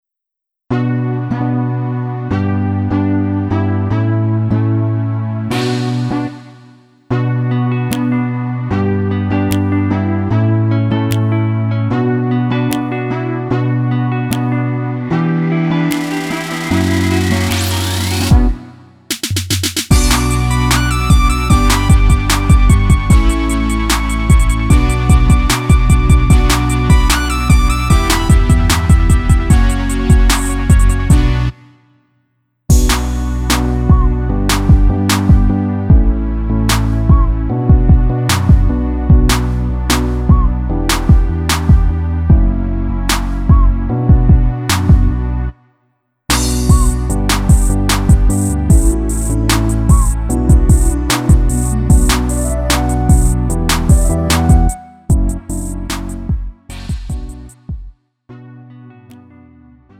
음정 원키 3:27
장르 구분 Lite MR